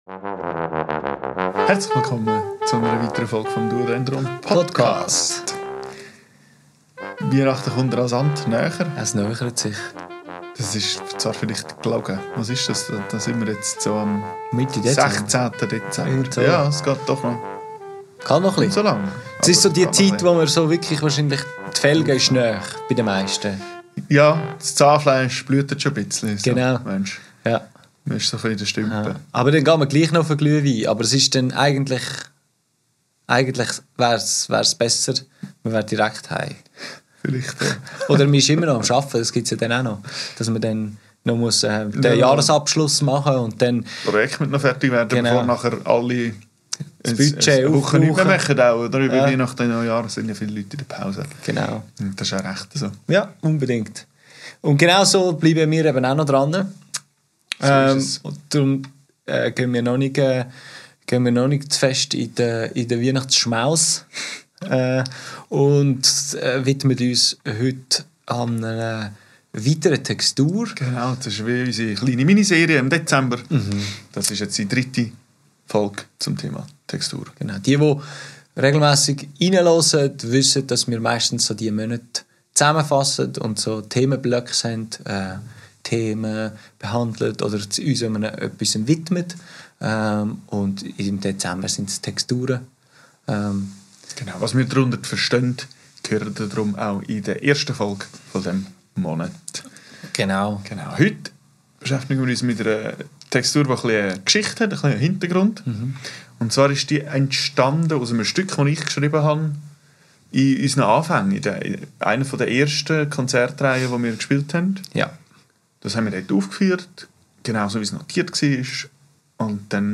Aufgenommen am 20.11.2024 im Atelier